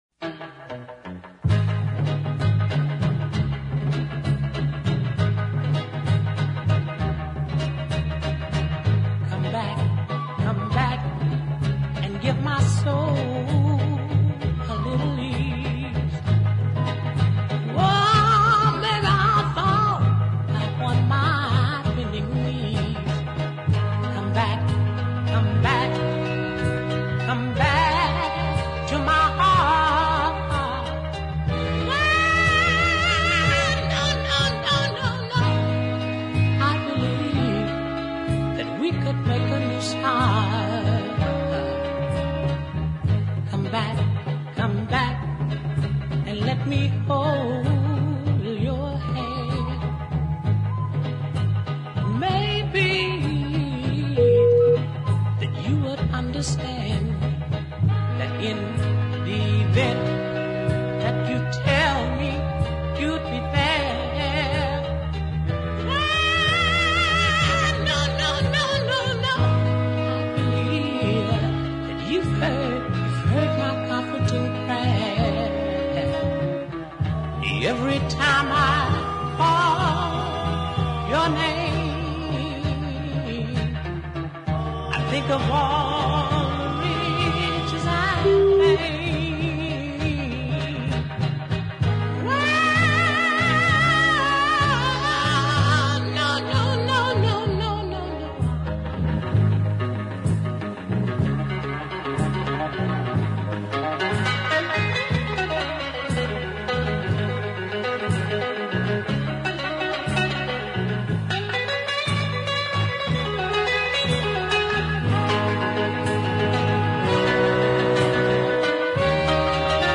blues ballad